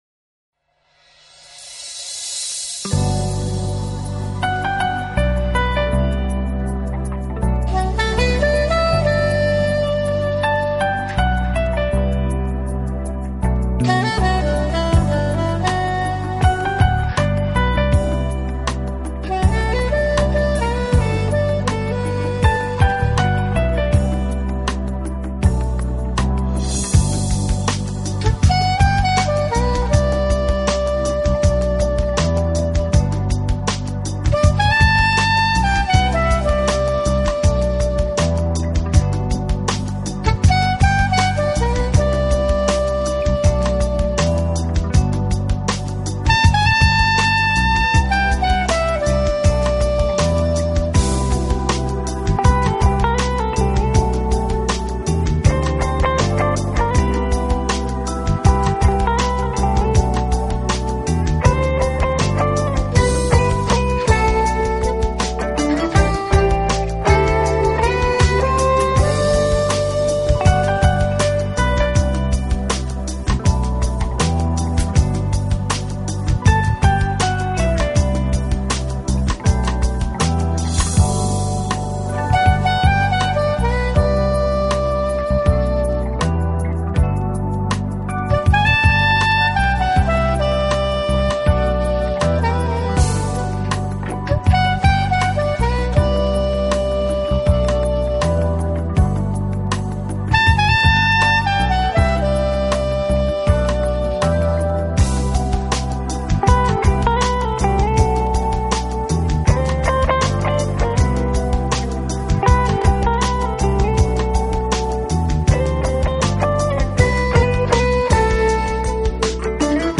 Genre : Instrumental
巨大力量，总的来说，它的柔和优美的音色，具有弦乐器的歌唱风格。